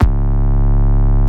808 (Maserati).wav